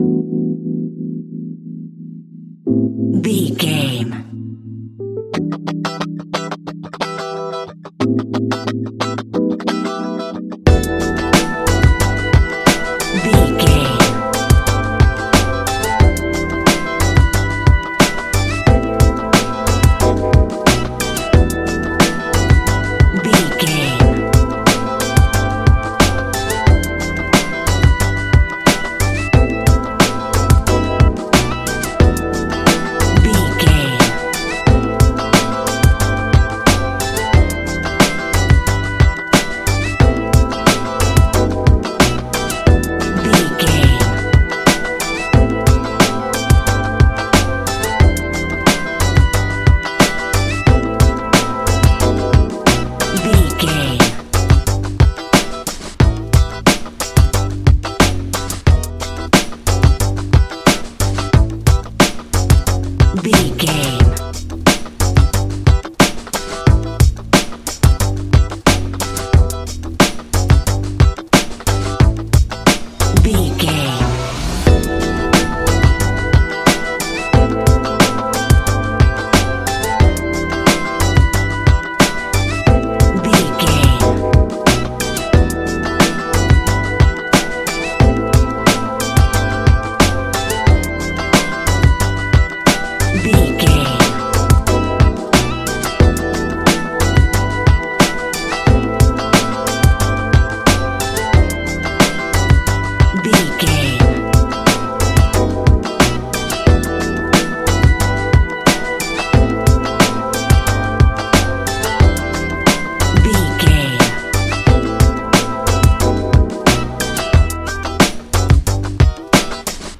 Ionian/Major
E♭
laid back
Lounge
sparse
new age
chilled electronica
ambient
atmospheric
instrumentals